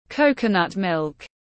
Sữa dừa tiếng anh gọi là coconut milk, phiên âm tiếng anh đọc là /ˈkəʊ.kə.nʌt ˌmɪlk/